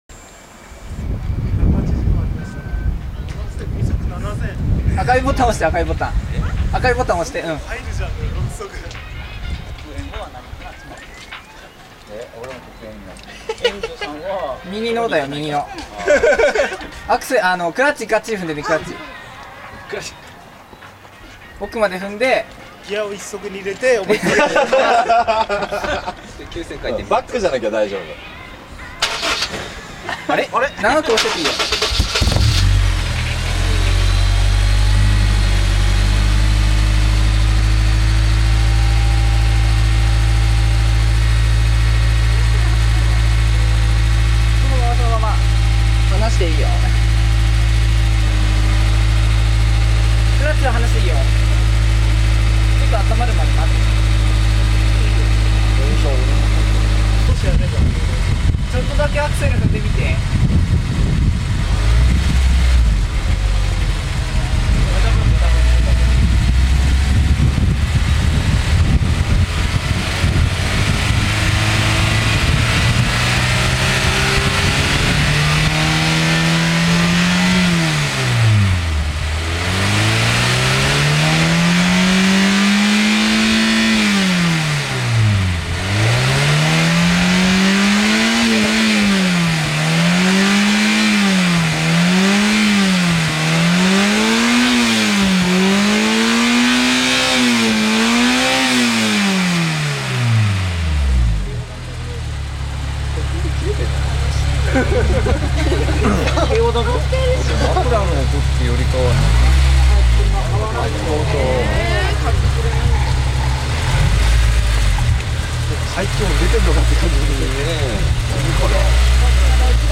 (今回はS2000マフラーサウンド録音オフだよ〜）
マイク：業務用ガンマイク
エキマニ・マフラー「アマデウス｣）　マフラーはやや抜け気味？